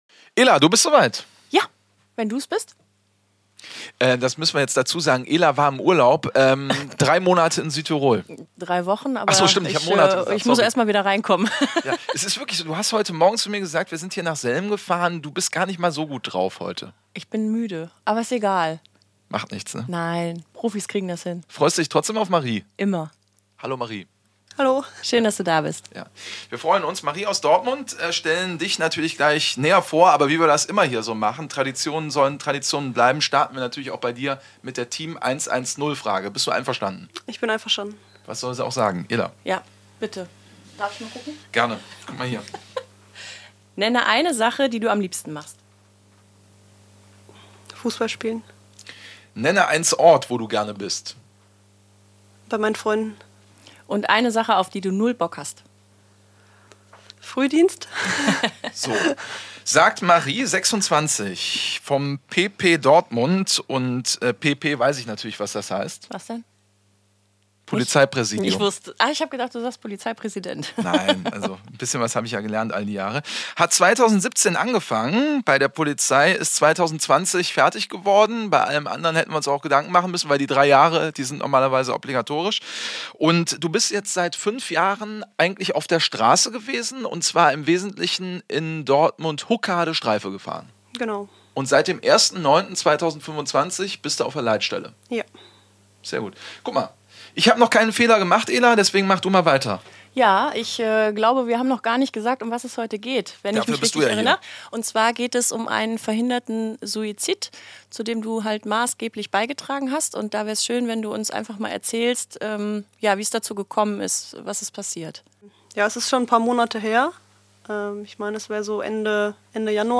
In dieser Folge ist eine Polizistin zu Gast, die von einem Moment erzählt, in dem es nicht um Schnelligkeit oder Stärke ging, sondern um Zuhören, Einfühlungsvermögen und die richtigen Worte zur richtigen Zeit. Ein ehrliches Gespräch über Verantwortung, mentale Belastung und die Frage, was Menschlichkeit im Polizeialltag wirklich bedeutet.